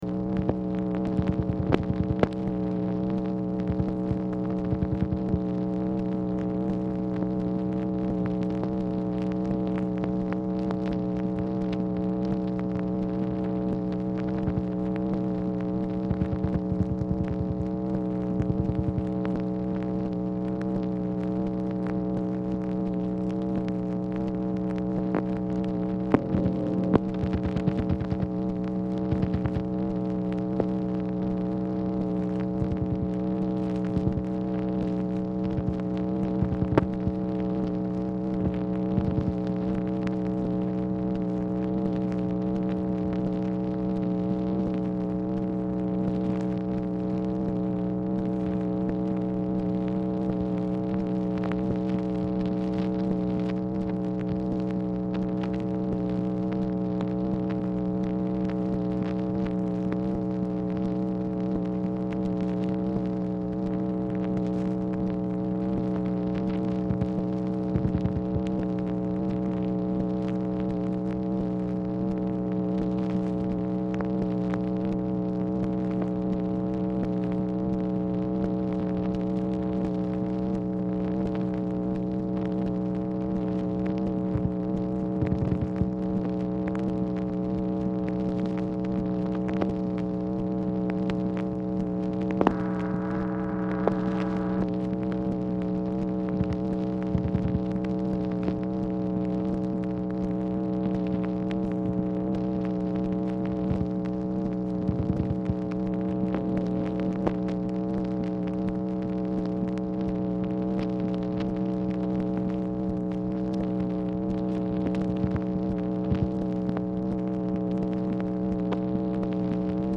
MACHINE NOISE
Oval Office or unknown location
Telephone conversation
Dictation belt